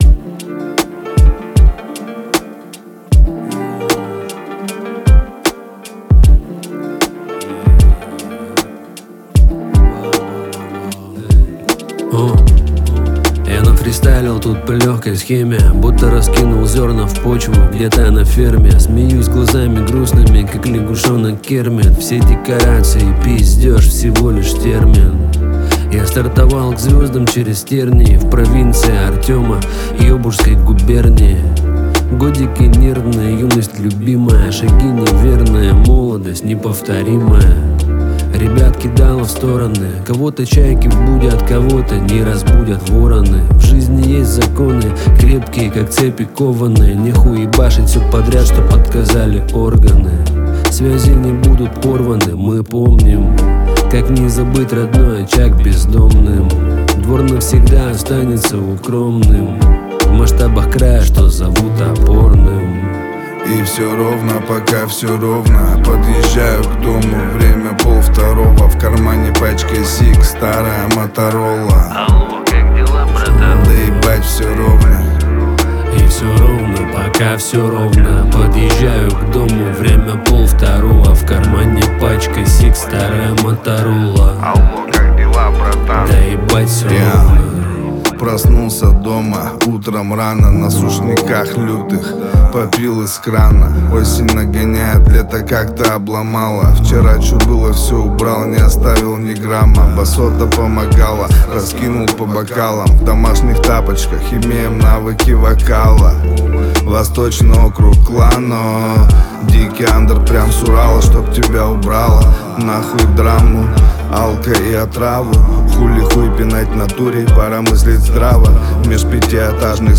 Жанр: Хип-хоп